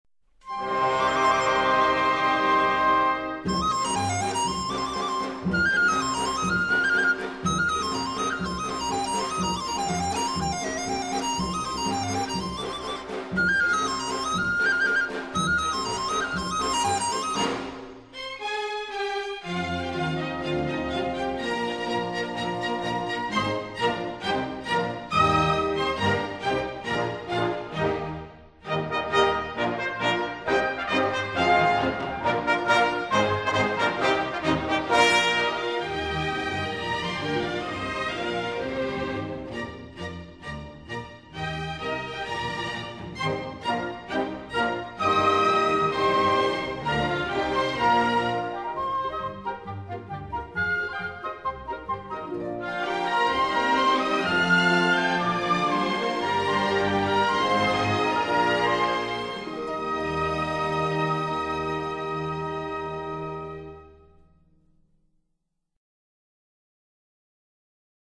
全部曲目充分结合了管弦乐器和民族乐器，使得音乐本身即更加立体感，又不失民族本色
录音地点：中国·上海
笛子
唢呐
二胡